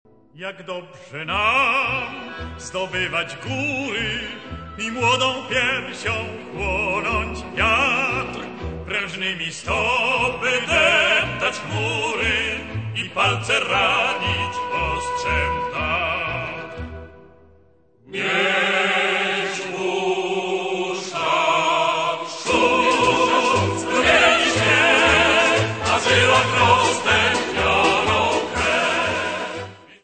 24 Polish Scout songs.